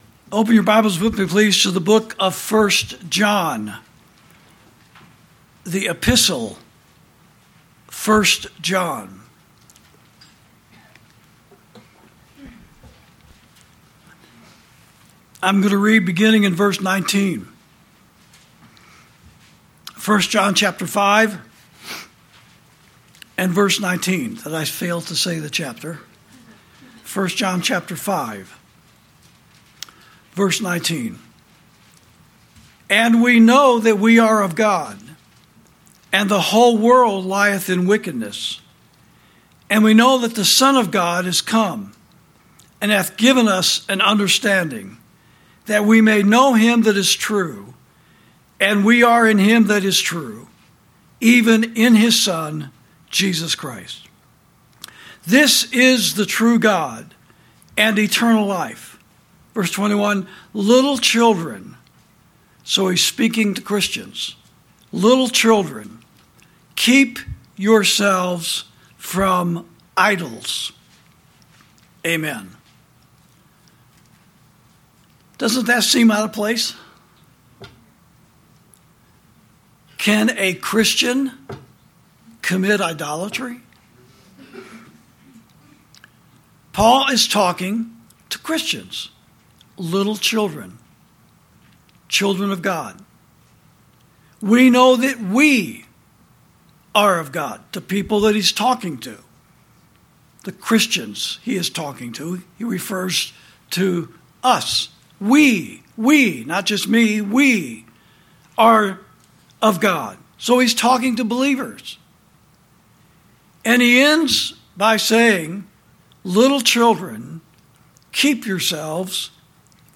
This message was preached by Pastor Chuck Baldwin on Sunday, November 2, 2025, during the service at Liberty Fellowship.